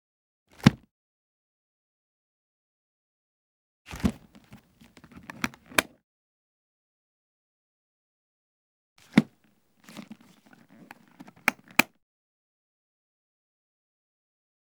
Leather Medical Bag Close and Lock Clasp Sound
household